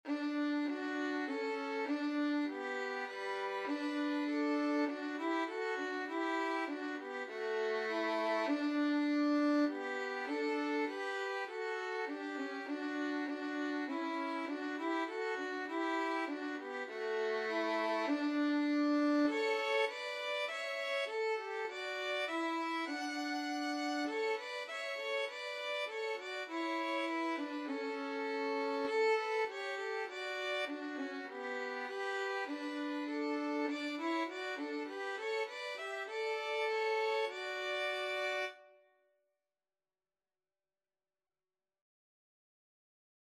Free Sheet music for Violin Duet
Violin 1Violin 2
D major (Sounding Pitch) (View more D major Music for Violin Duet )
4/4 (View more 4/4 Music)
Traditional (View more Traditional Violin Duet Music)